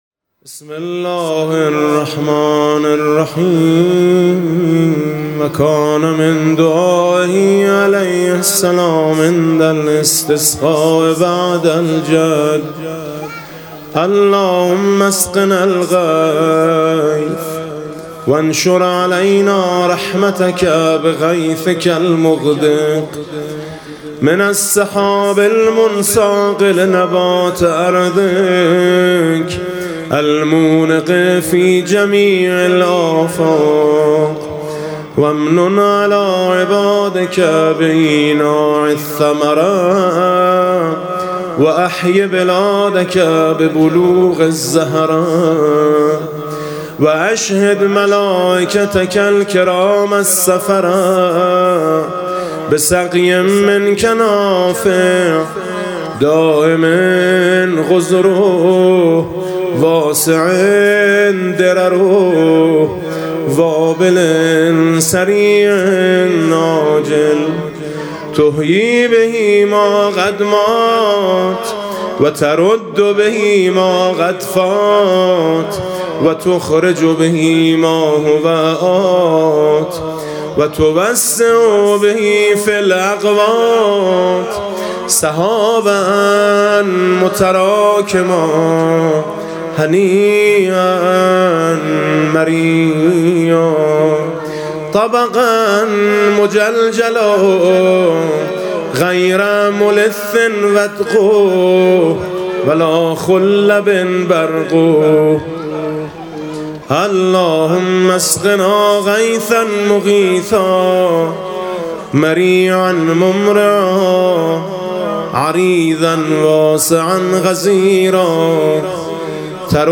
هیأت دخترانه به مناسبت عزاداری ایام شهادت حضرت زهرا (س) و استغاثه طلب باران (قرائت دعای ۱۹ صحیفه سجادیه)
با مداحی: حاج میثم مطیعی
هم‌خوانی دختر خانم‌ها با حاج میثم مطیعی: دخترِ توام زهرا